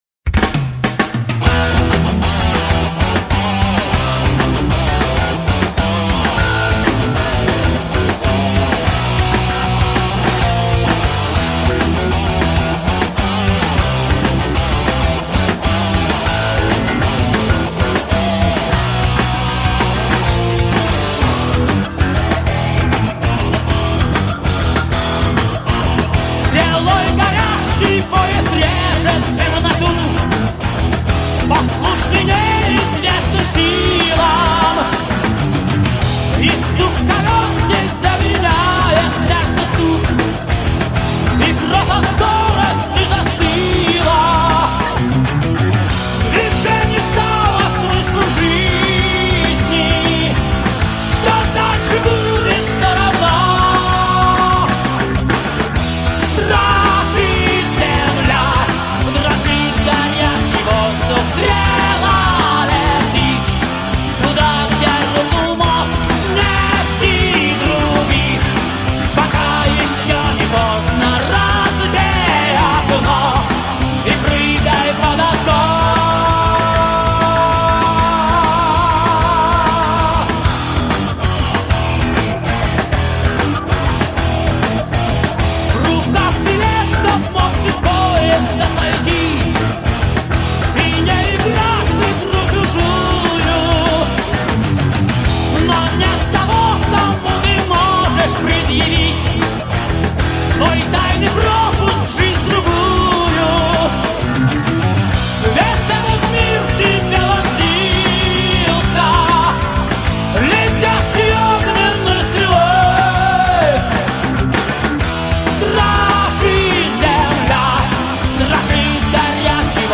вокал
гитара
бас
барабаны